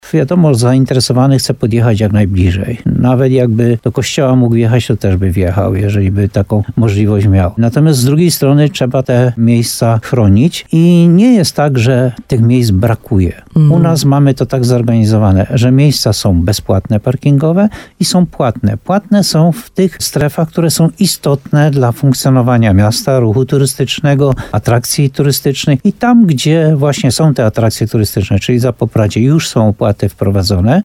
Posłuchaj rozmowy z burmistrzem Muszyny Janem Golbą: Tagi: Muszyna Słowo za Słowo Krynica-Zdrój Jan Golba Parking Zapopradzie HOT parking płatny